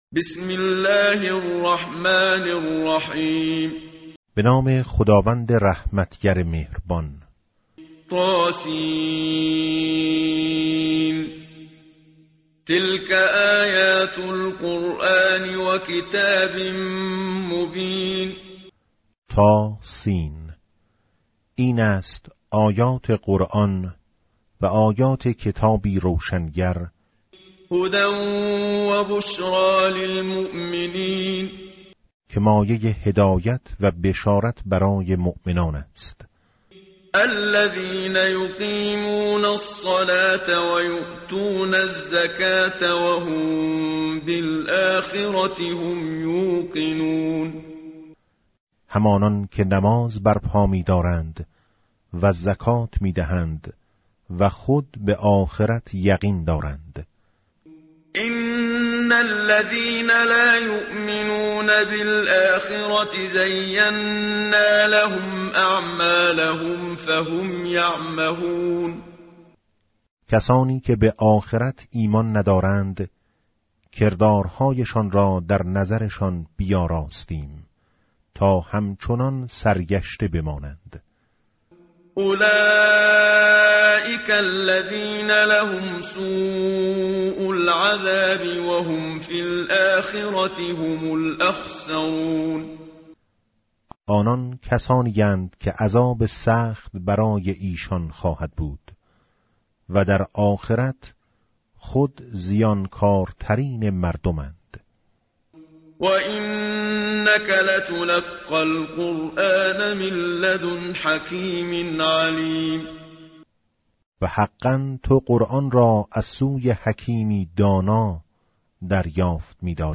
متن قرآن همراه باتلاوت قرآن و ترجمه
tartil_menshavi va tarjome_Page_377.mp3